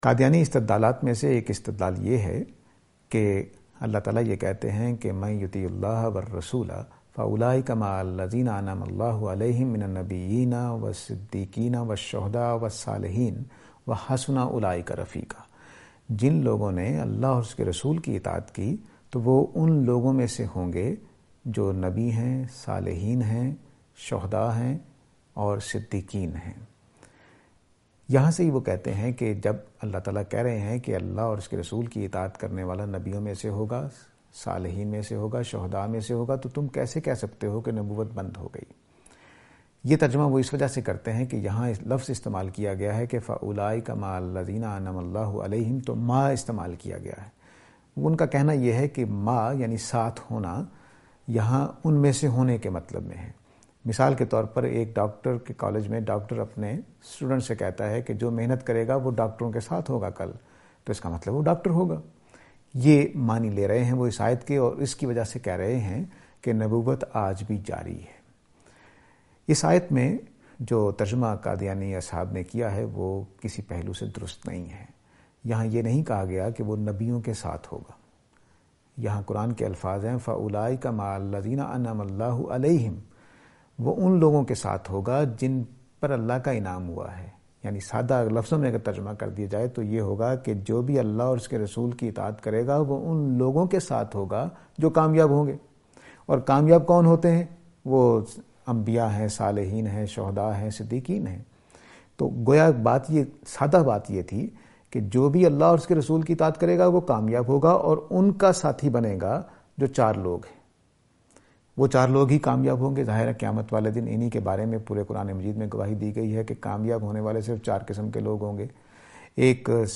This sitting is an attempt to deal with the question 'Being a prophet and Siddique’.